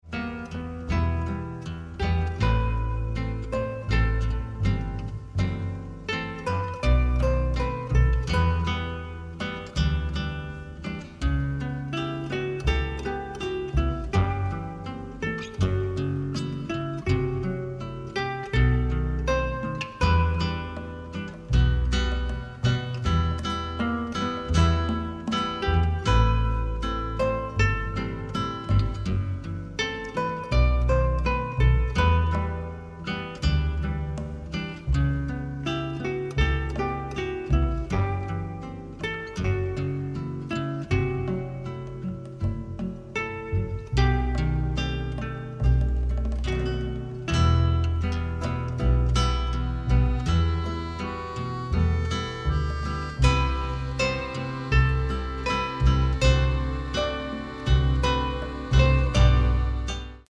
Additional Music (mono)